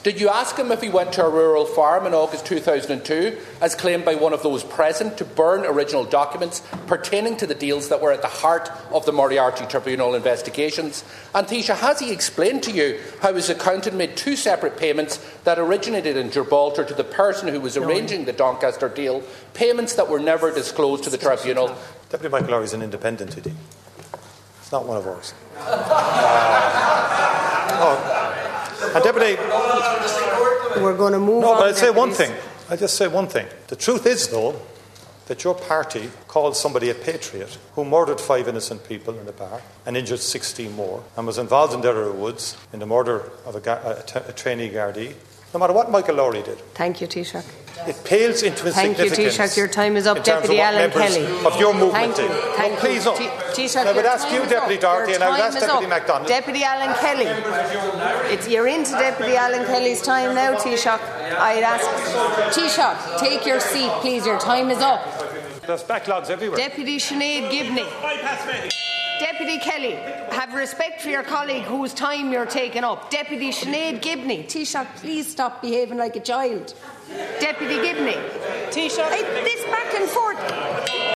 Rowdy scenes prompted Ceann Comhairle Verona Murphy to intervene: